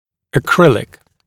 [ə’krɪlɪk][э’крилик]акриловый материал, акриловая пластмасса; акриловый